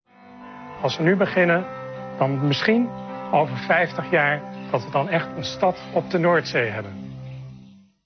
gesproken commentaar